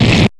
strike07.wav